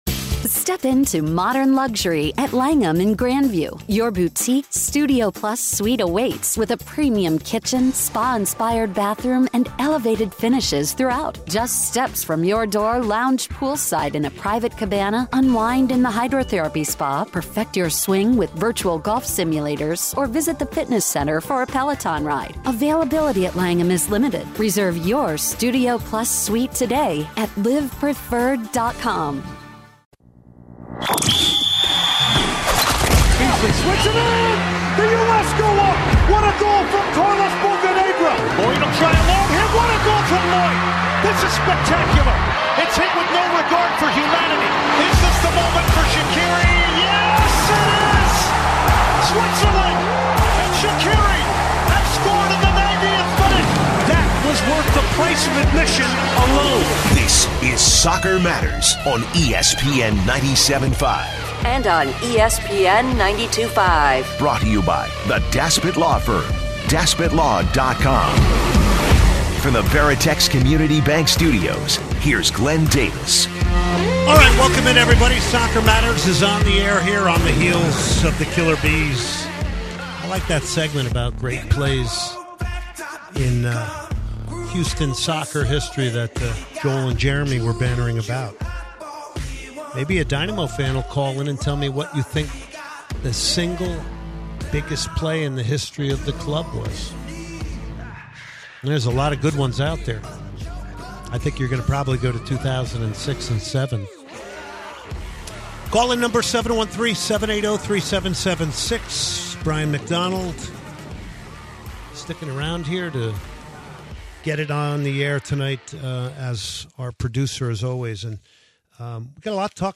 A look ahead to the Dynamo season opener this weekend against their rivals FC Dallas A conversation with 4-time MLS Champion Dwayne De Rosario, who won two titles with the Dynamo, ahead of the 20th anniversary season for the Dynamo